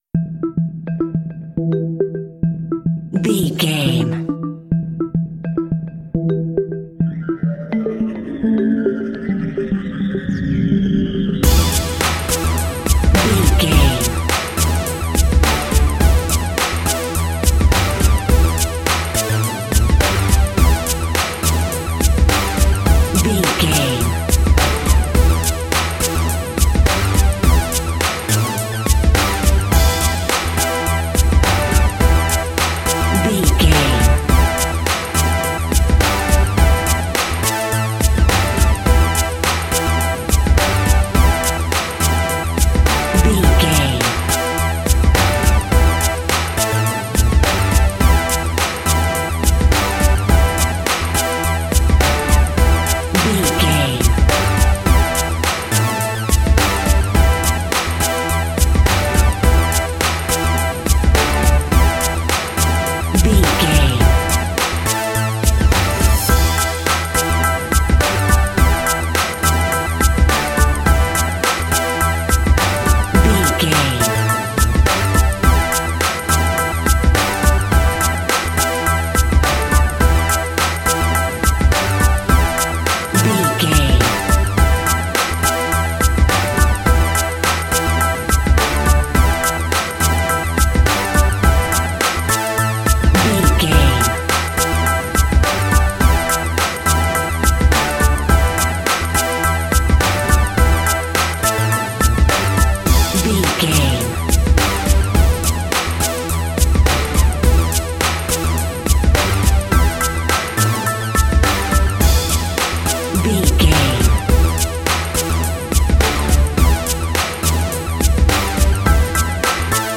Rappers Instrumental Music.
Aeolian/Minor
hip hop
chilled
laid back
groove
hip hop drums
hip hop synths
piano
hip hop pads